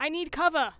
Barklines Combat VA
Leo cover me.wav